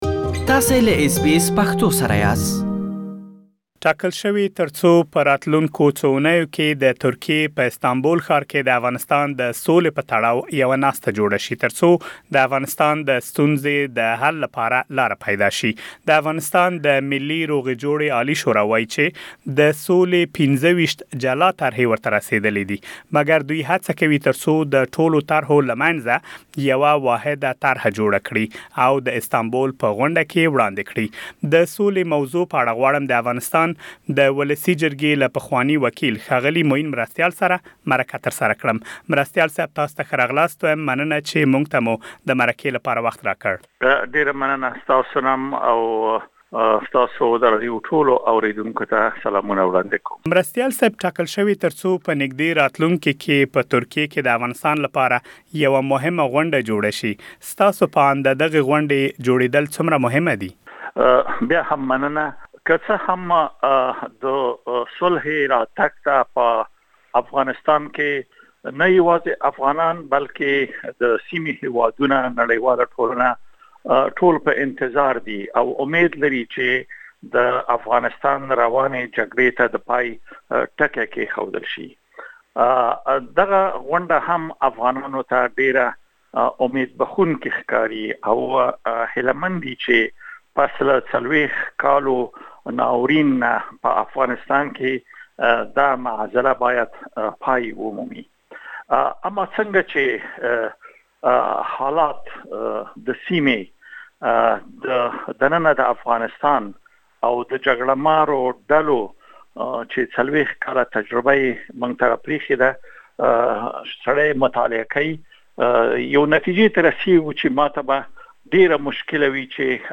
تاسو کولای شئ، مرکه دلته واورئ.